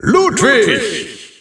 Announcer pronouncing Ludwig in Spanish.
Ludwig_German_Announcer_SSBU.wav